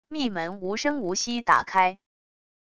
密门无声无息打开wav音频